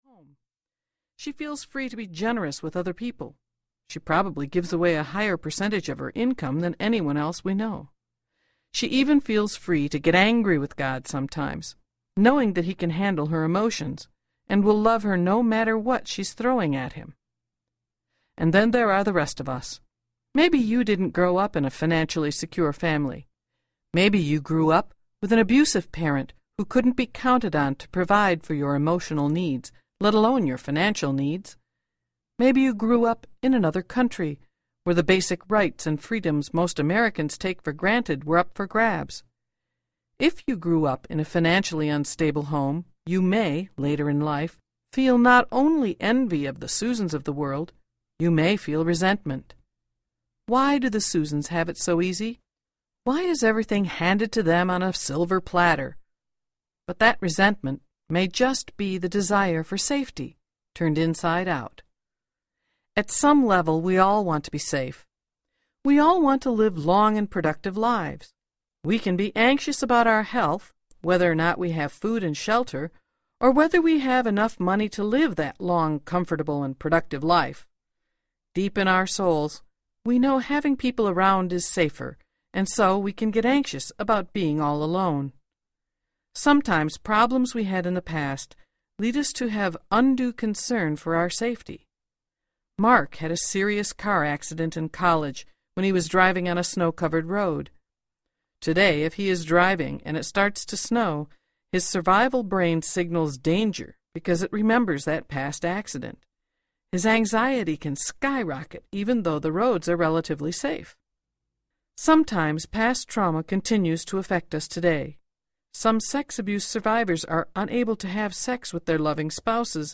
The Seven Desires of Every Heart Audiobook